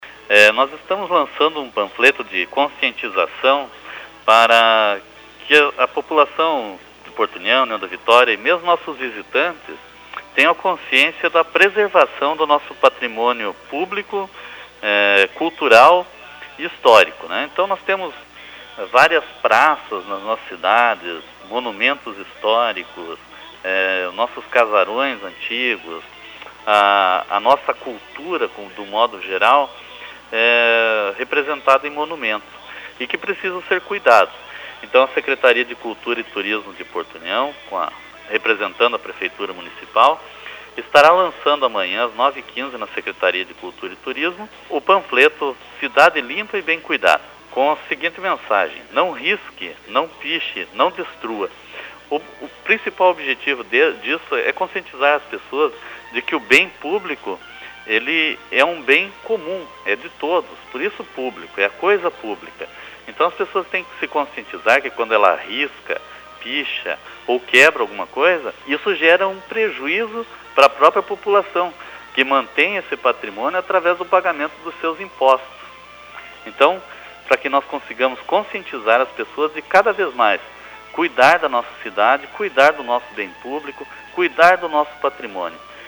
HOJE-CULTURA-PARTE-01-JOSÉ-CARLOS-FALA-DO-LANÇAMENTTO-DO-PROJETO-PORTO-UNIÃO-CIDADE-LIMPA-E-BEM-CUIDADA.mp3